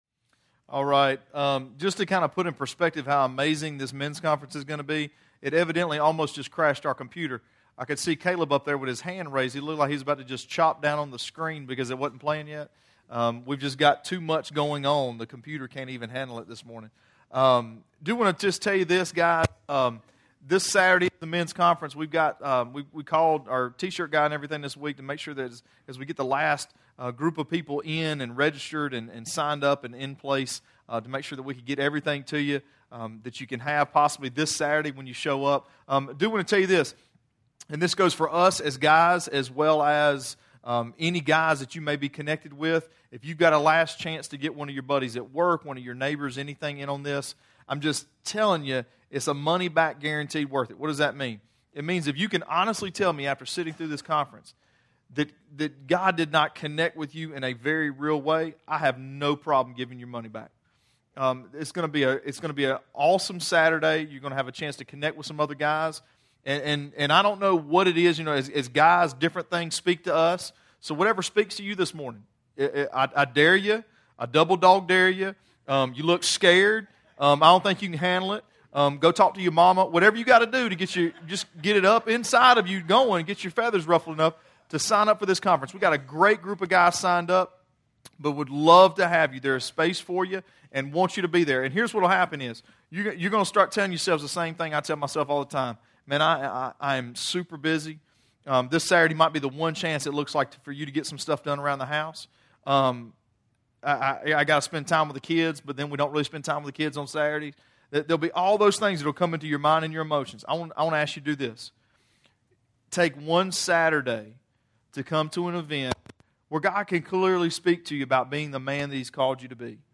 Sermons Archive - Page 49 of 60 - REEDY FORK COMMUNITY CHURCH